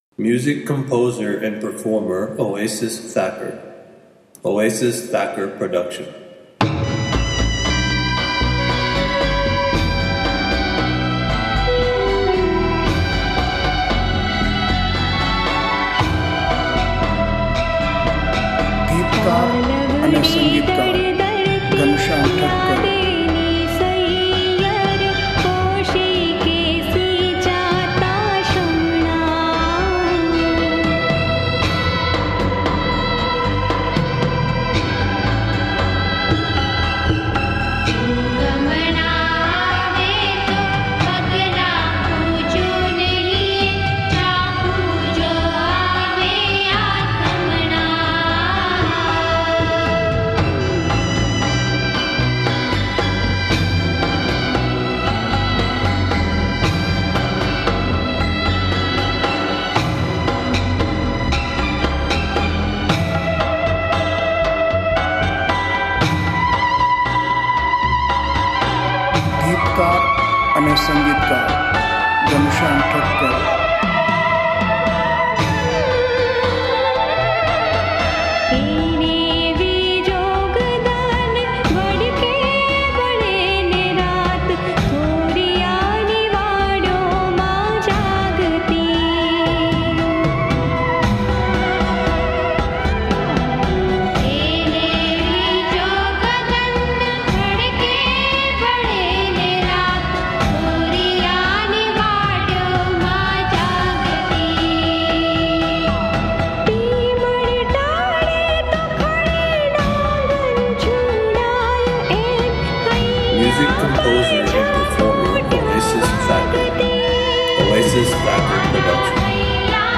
Chorous